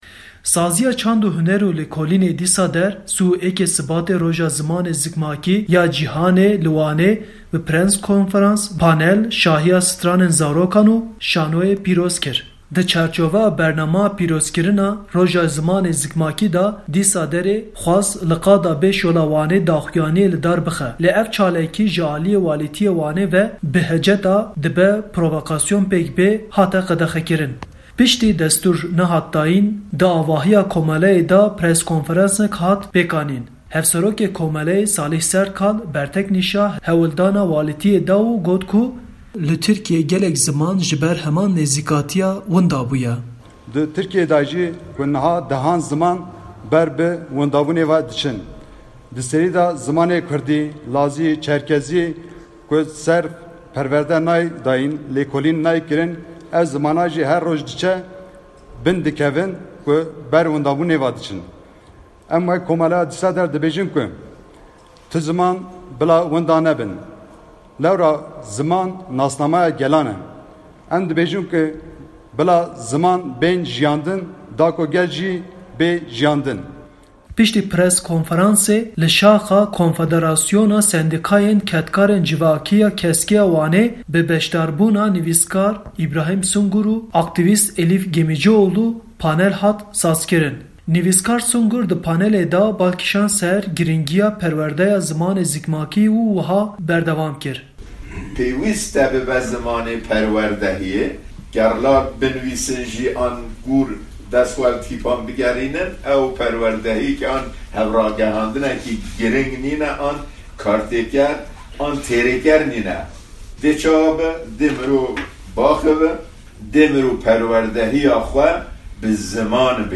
Piştî merasîmê zorakan stranên Kurdî lorandin.